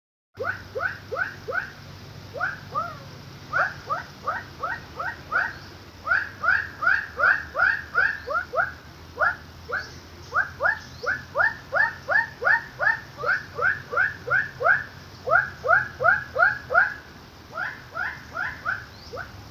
Play call
hylarana-glandulosa_tmn-rimba.mp3